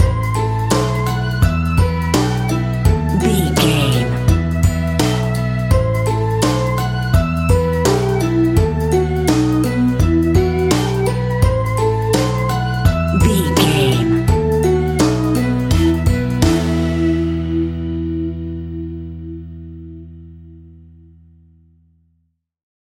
Aeolian/Minor
instrumentals
childlike
happy
kids piano